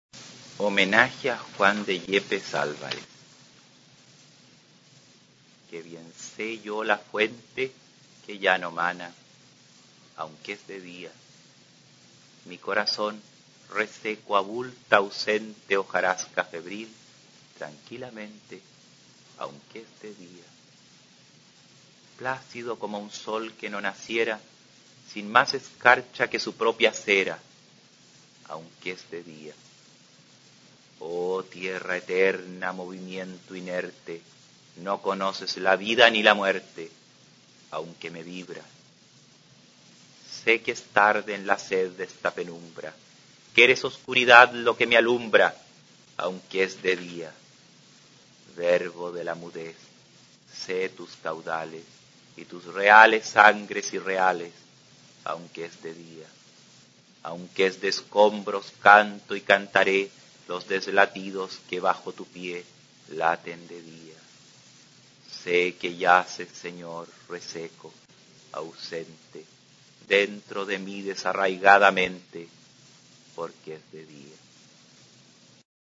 Aquí se puede oír al escritor chileno David Rosenmann-Taub leyendo su Homenaje a Juan de Yepes Álvarez, donde utiliza la misma versificación que el poema del místico español que está glosando.